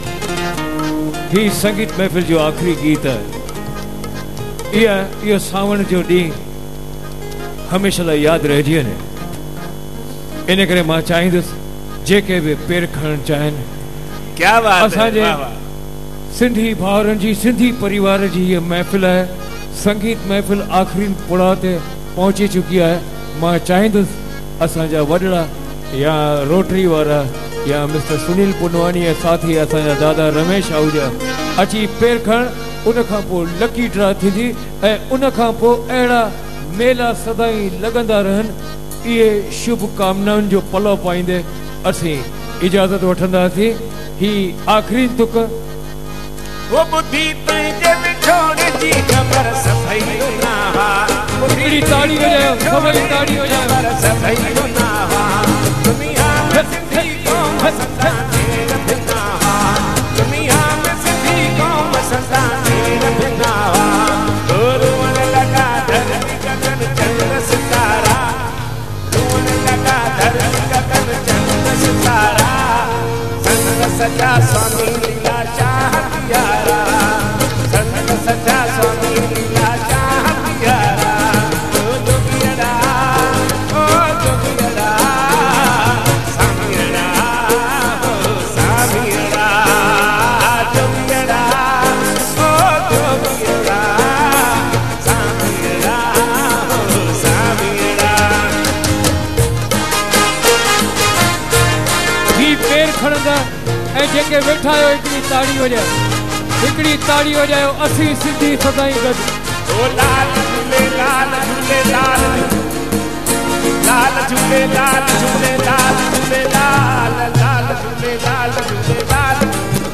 Performance at Town Hall, Sindhunagar 3
Live Performance